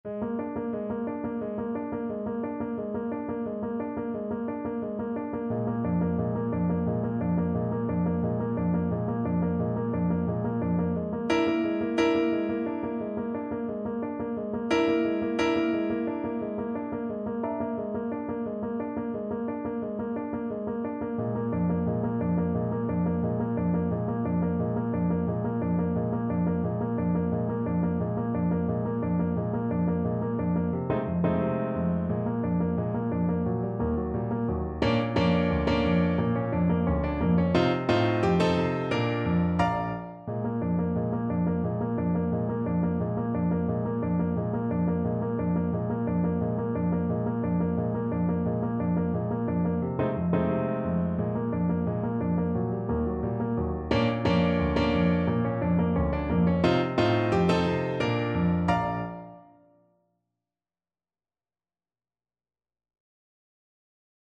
2/2 (View more 2/2 Music)
F5-D6
Relentlessly forward! =c.88